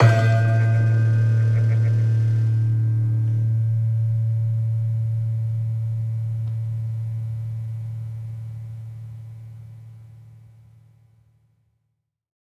Gamelan Sound Bank
Gender-1-A1-f.wav